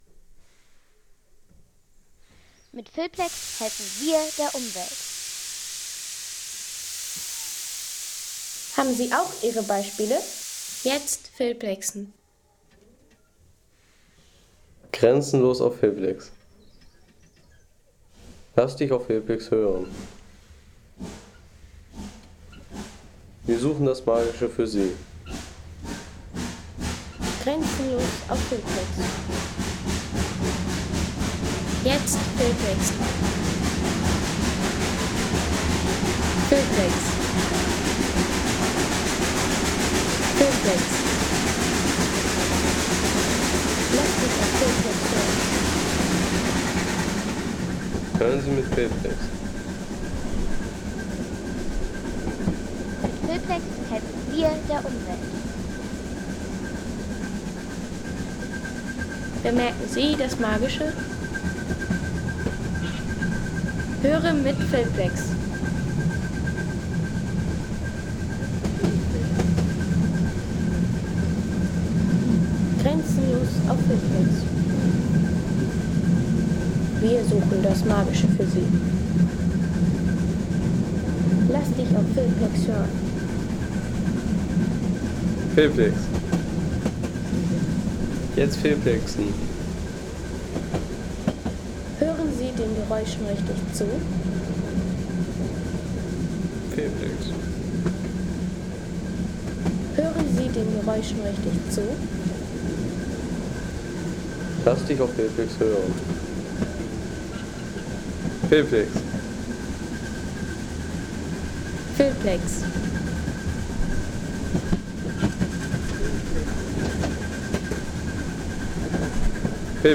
Dampflokomotive läuft an
Die Dampflokomotive in voller Fahrt.